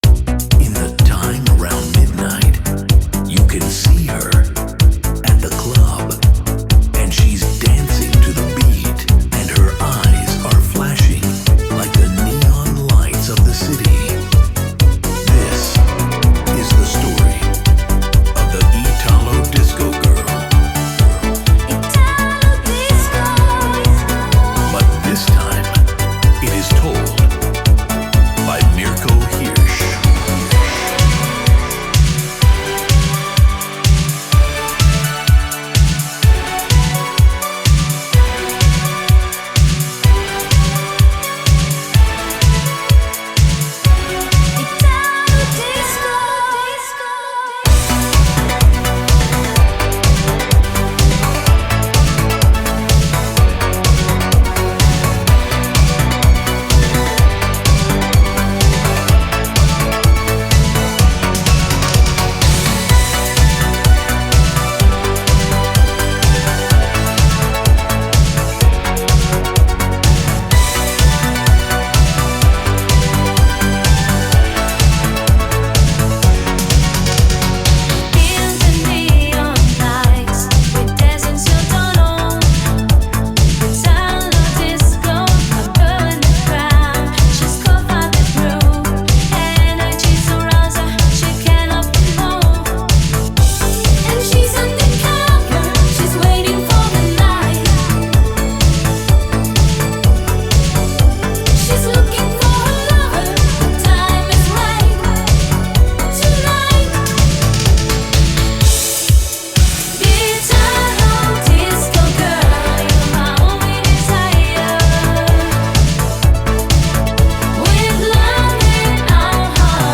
Ну и погнали, немного)) Бодрячковые такие вещи)